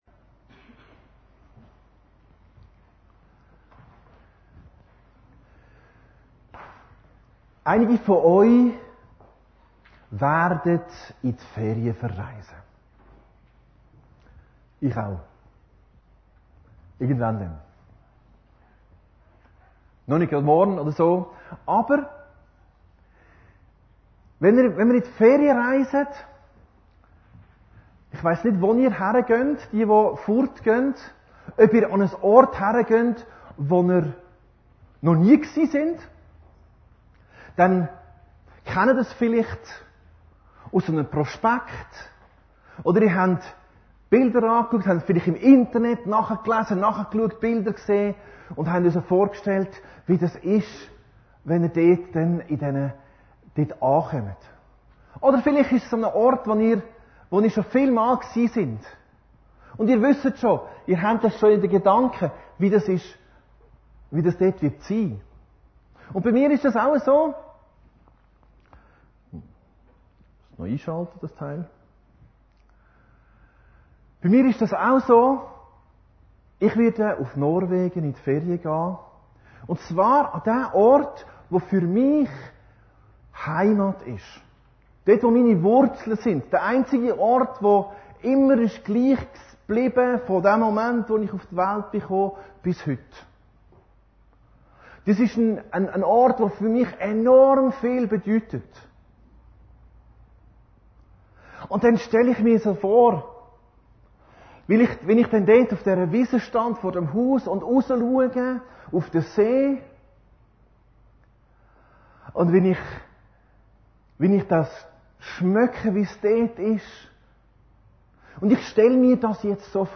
Predigten Heilsarmee Aargau Süd – Der konkrete Himmel 3